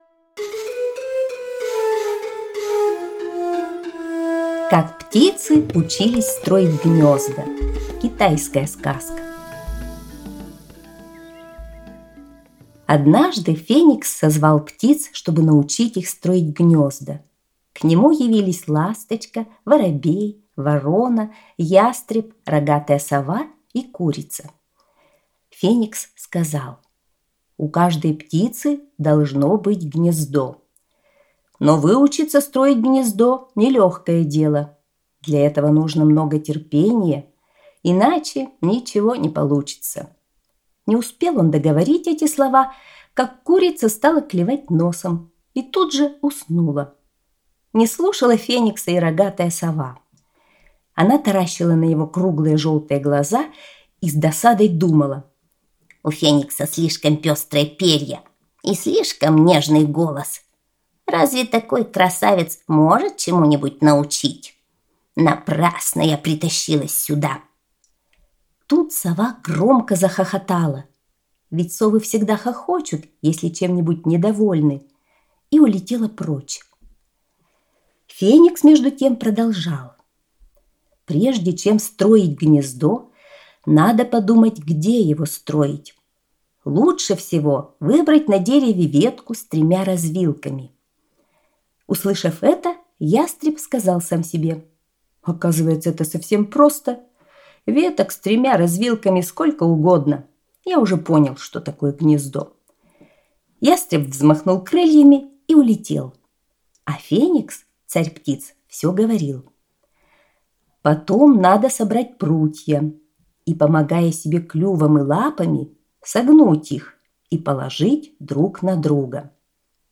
Как птицы учились строить гнёзда – китайская аудиосказка